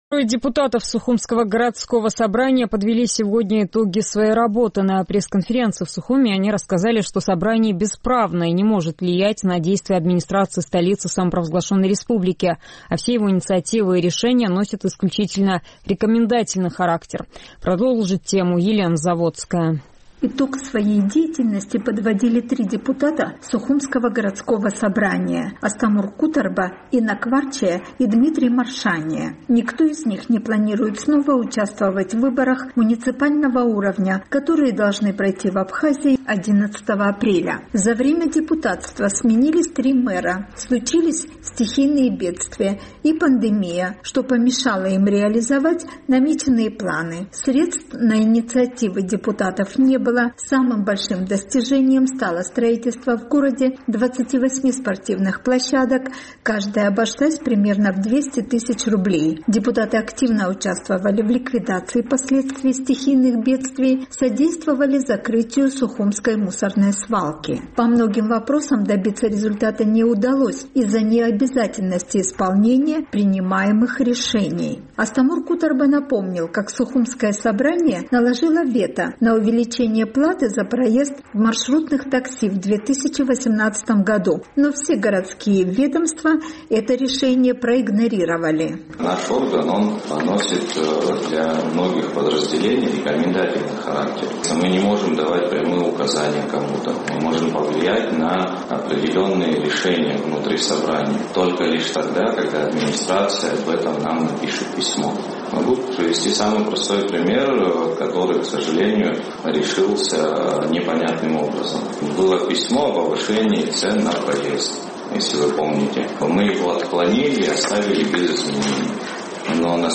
Трое депутатов Сухумского городского собрания подвели итоги своей работы. На пресс-конференции в Сухуме они рассказали, собрание не может влиять на действия администрации, а все его инициативы и решения носят исключительно рекомендательный характер.